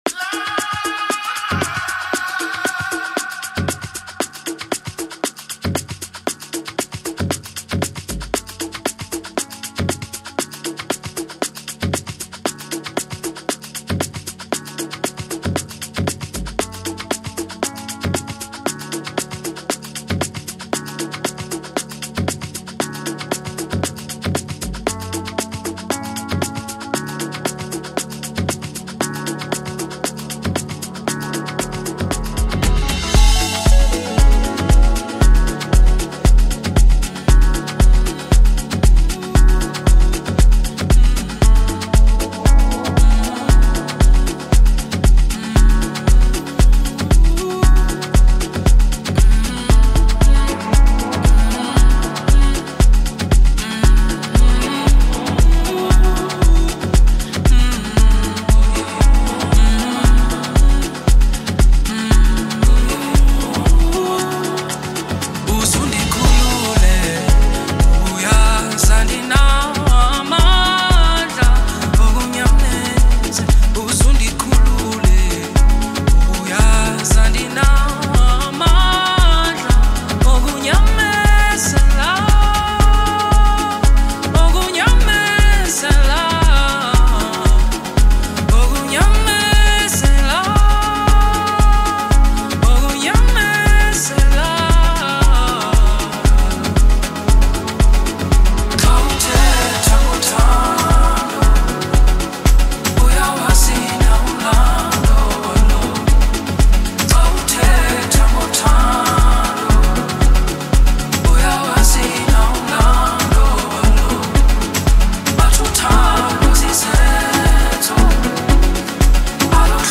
Foreign MusicSouth African